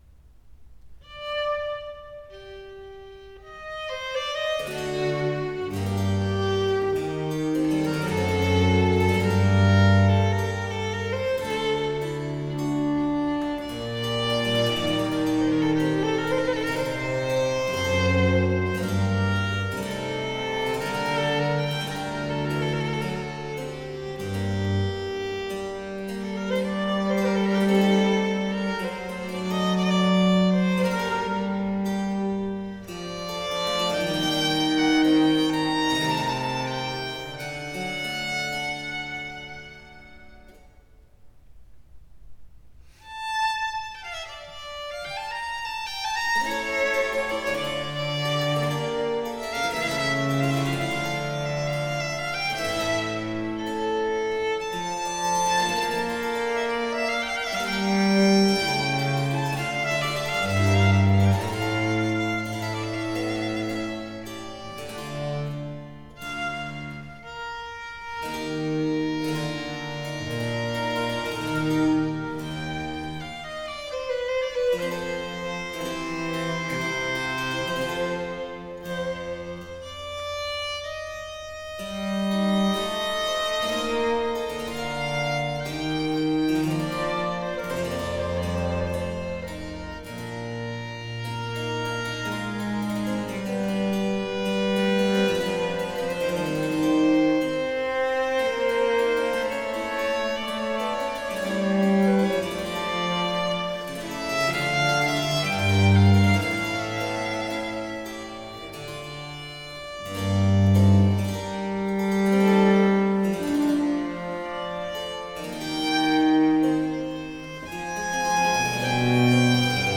OLED SIIN ▶ muusika ▶ Klassika